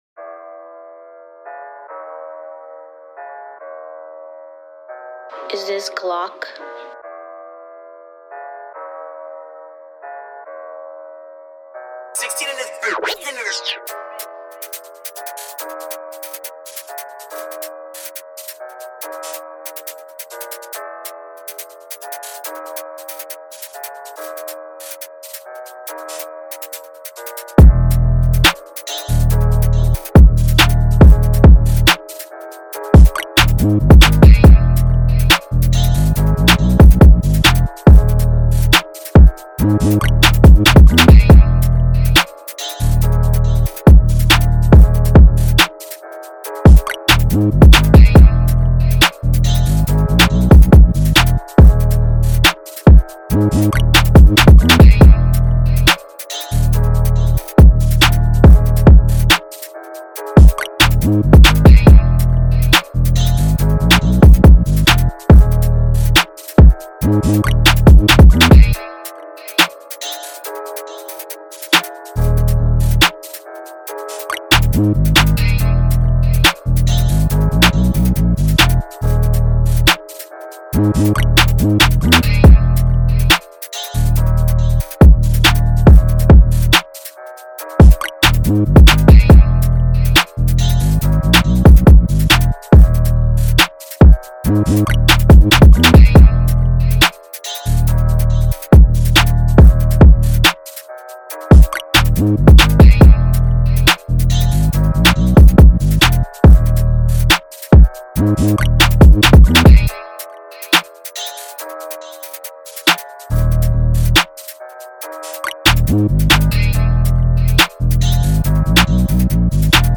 2020 in NY Drill Instrumentals